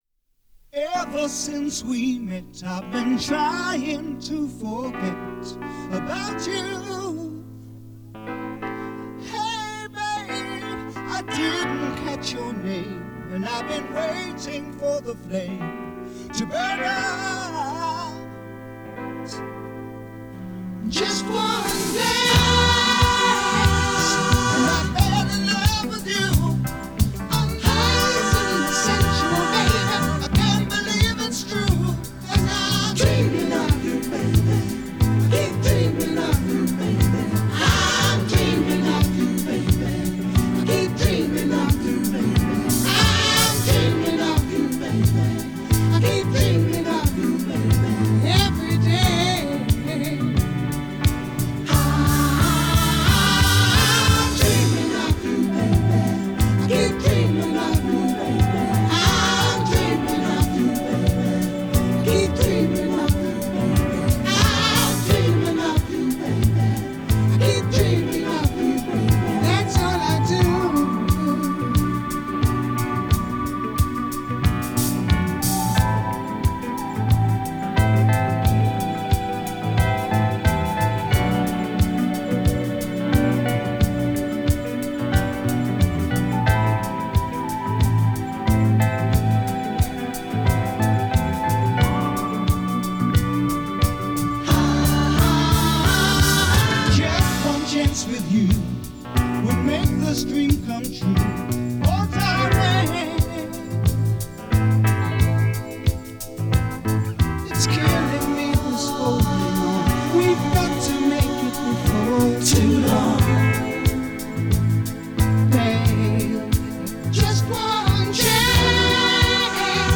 стили фанк и соул с элементами поп-рока и регги